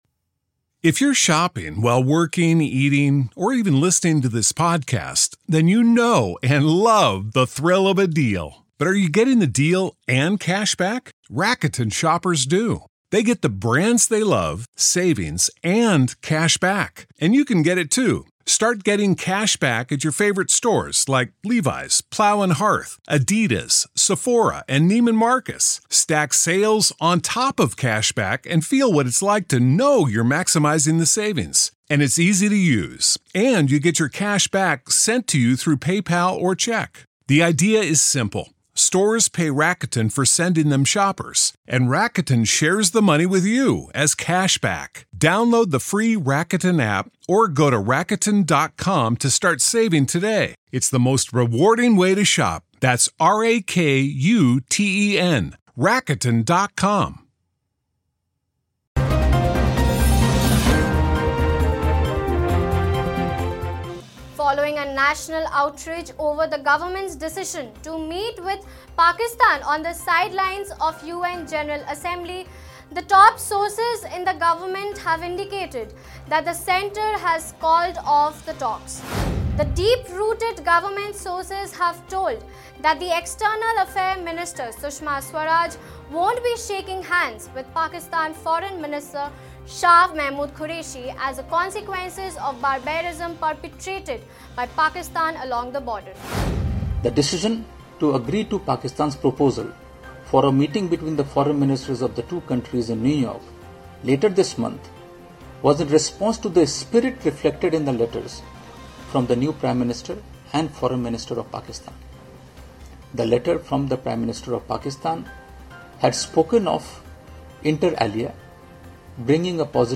News Report / India calls off the meeting with Pakistan, terms it a double game of the neighbouring country?